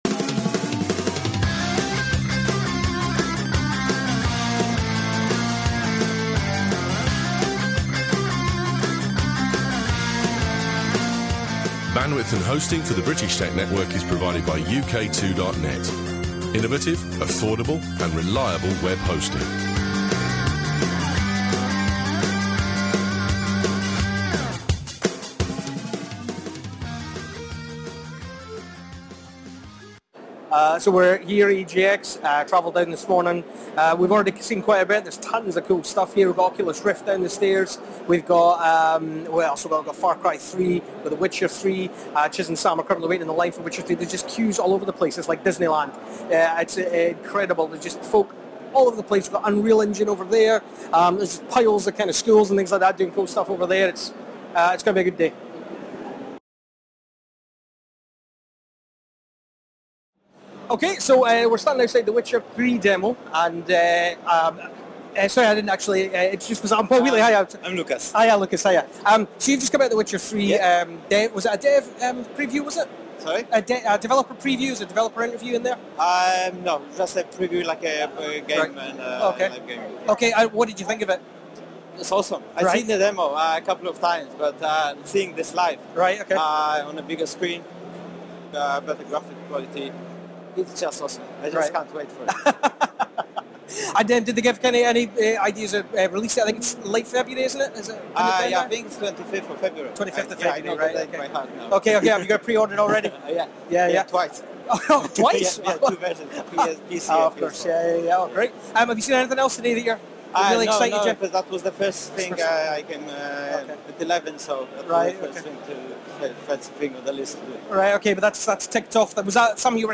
GAMER_EGX_2014.mp3